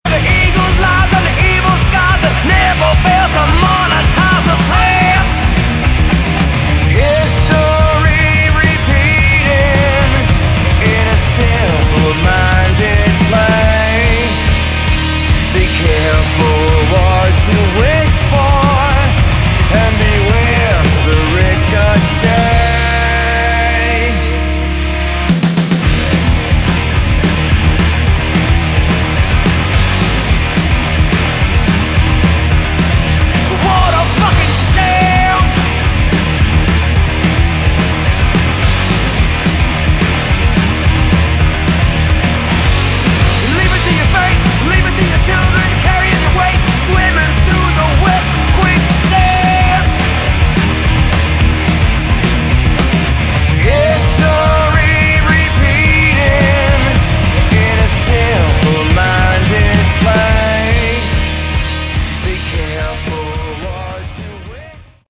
Category: Hard Rock
bass, vocals
guitar
drums